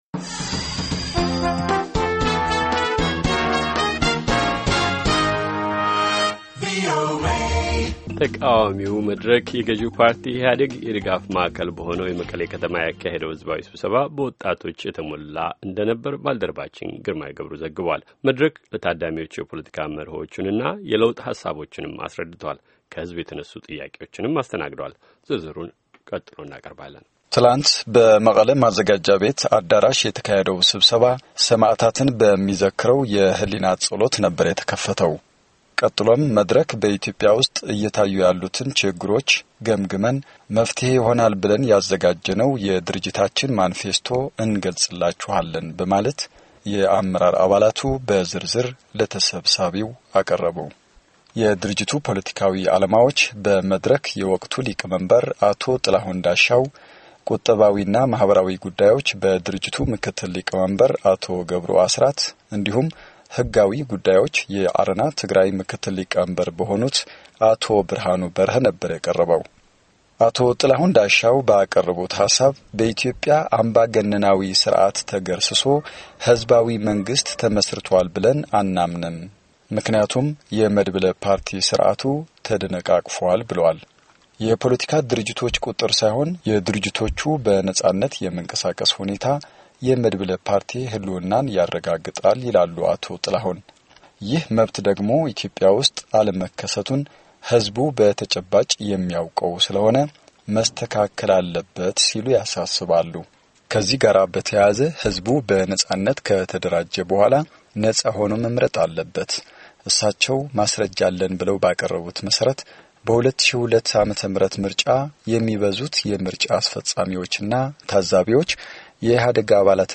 የኢትዮጵያ ዴሞክራሲያዊ መድረክ ፓርቲ ባለፈው ዕሁድ መቀሌ ላይ ከፍተኛ ሕዝባዊ ስብስባ አካሂዷል።